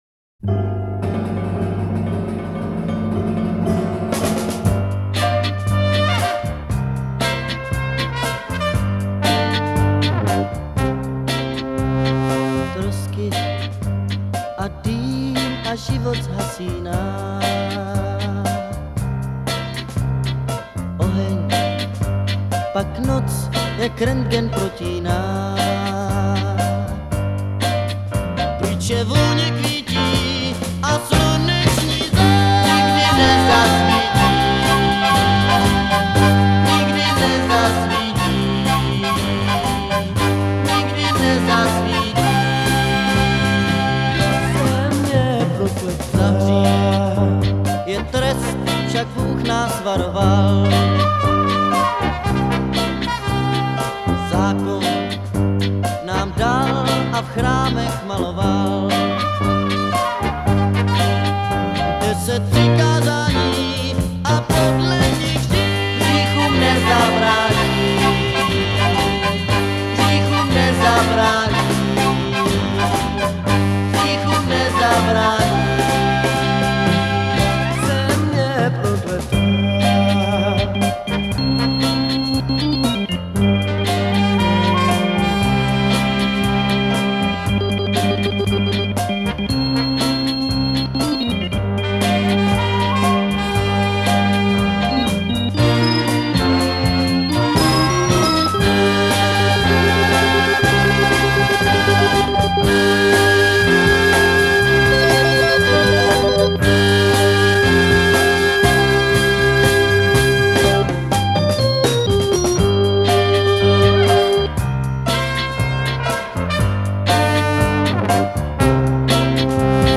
basová kytara
bicí nástroje